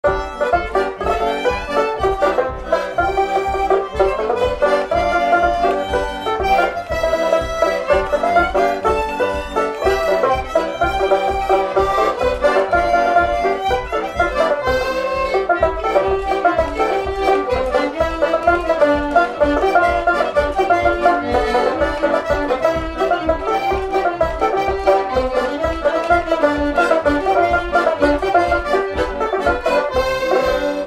Tampon (Le)
Instrumental
danse : séga
Pièce musicale inédite